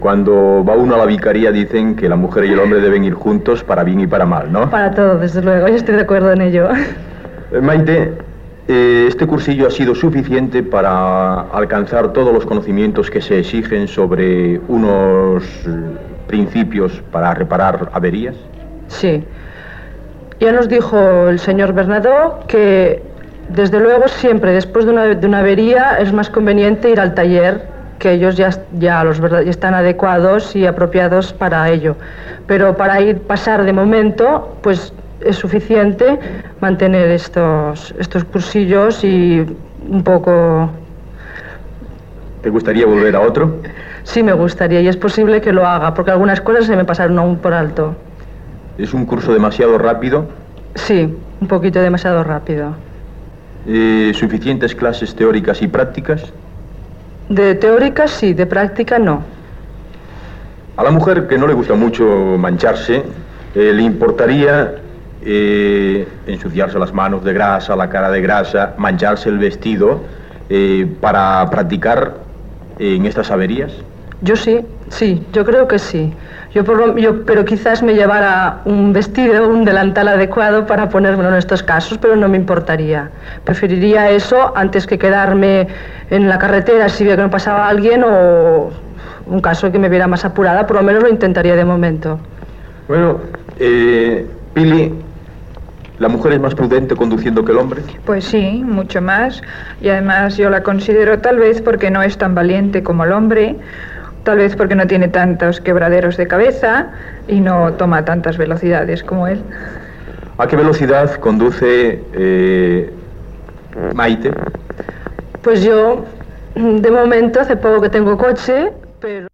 Entrevista a un parell de dones sobre un curs per reparar avaries de cotxes i sobre l'estil de conducció de les dones